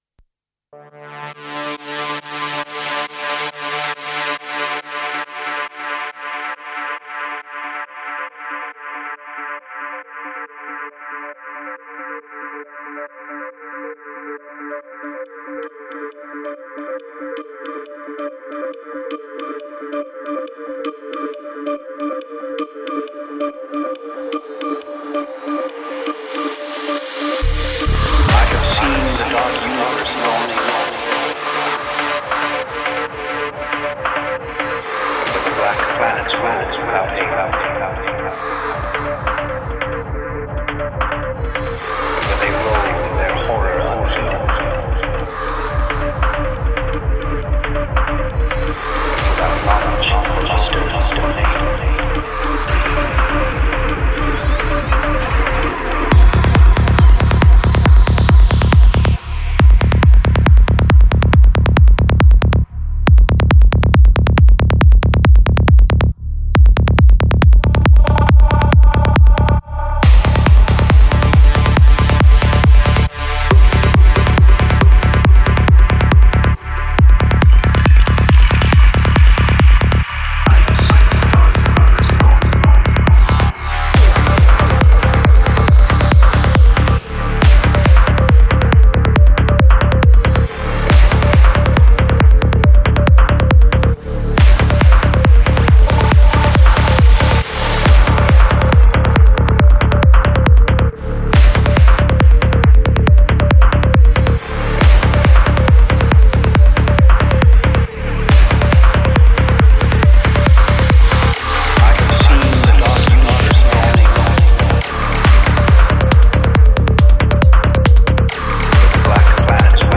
Psy-Trance